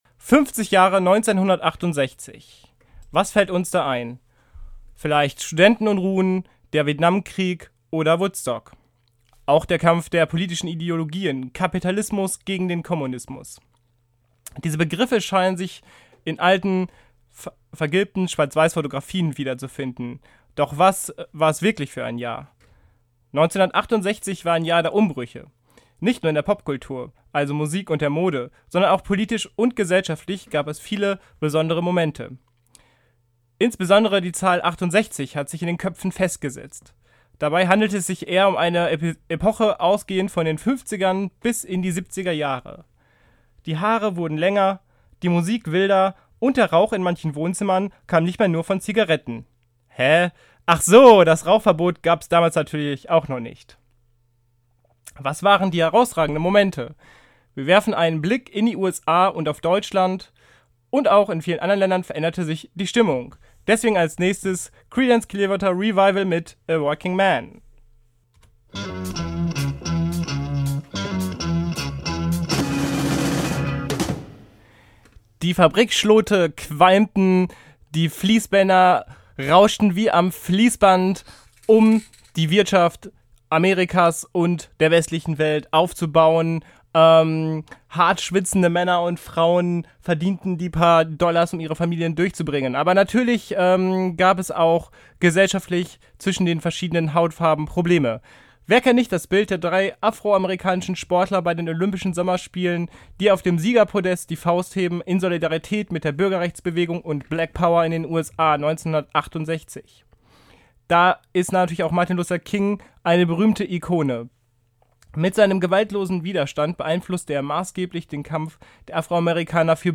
Die Musikpassagen wurden aus GEMArechtlichen Gründen gekürzt.